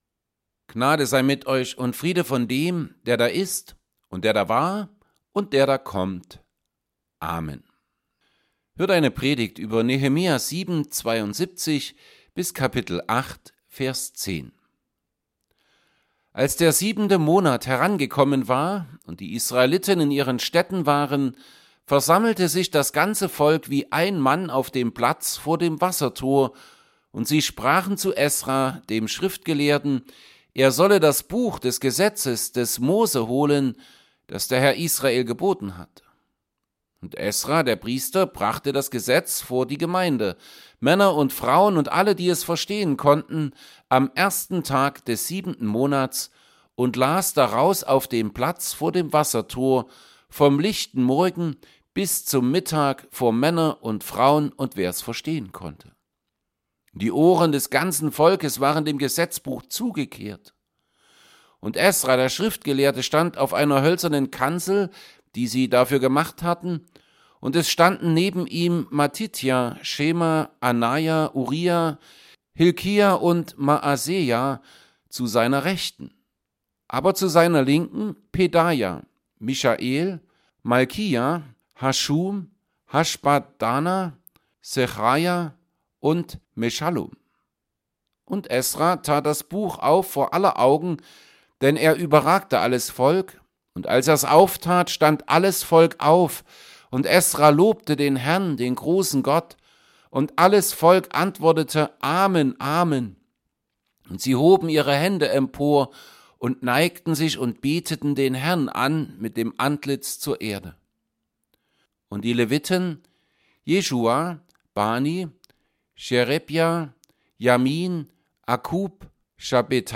Nehemiah 7:72-8:10 Gottesdienst: Gottesdienst %todo_render% Dateien zum Herunterladen Notizen « 2.
Predigt_zu_Nehemia_8_1b10.mp3